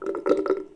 sink-churprre
bath bubble burp click drain dribble drip drop sound effect free sound royalty free Sound Effects